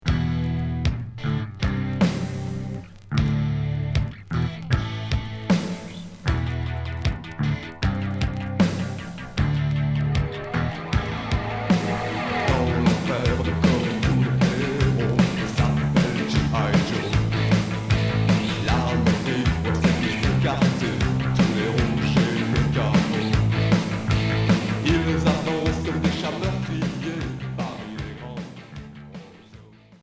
Cold wave Deuxième 45t retour à l'accueil